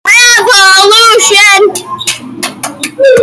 turn on loop to do this soccer chant.